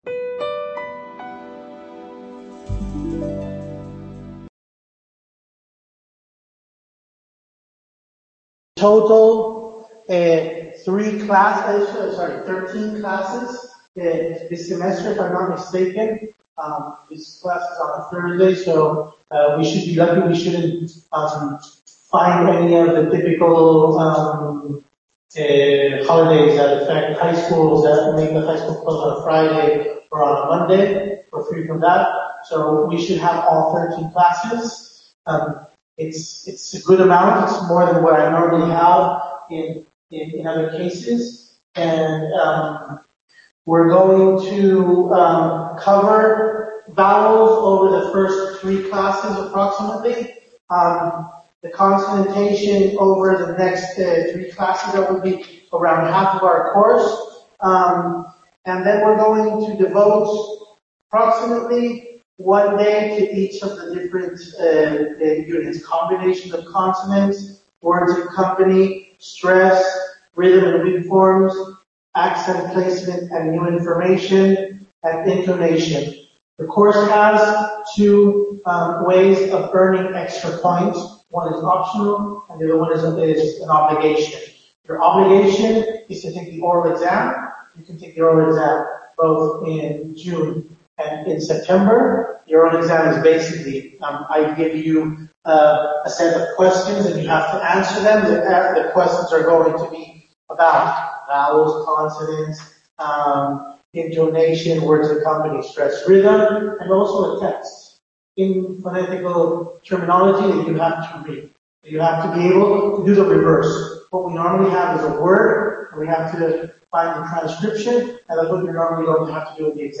Videoconference for Pronunciación de Lengua Inglesa